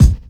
• Studio Kick Sample C Key 88.wav
Royality free kick drum single shot tuned to the C note. Loudest frequency: 305Hz
studio-kick-sample-c-key-88-m7J.wav